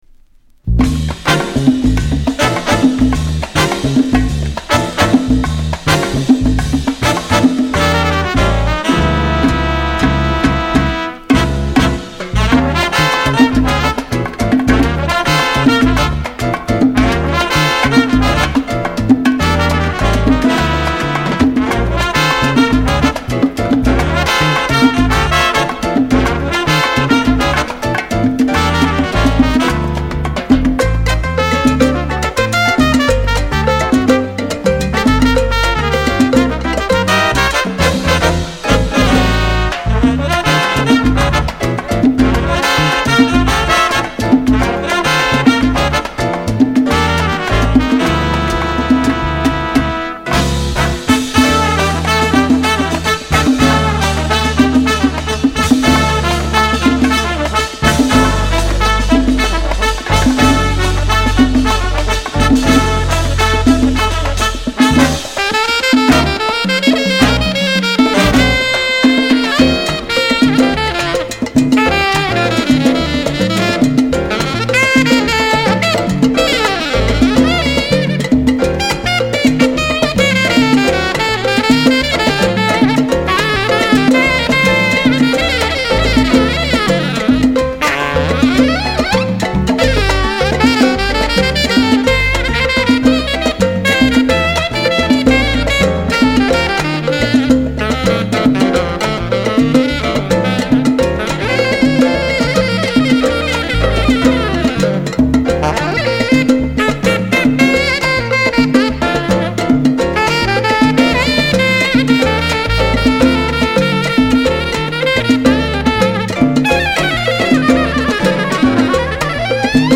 Latin sweden
パーカッシヴなラテン・アレンジで調理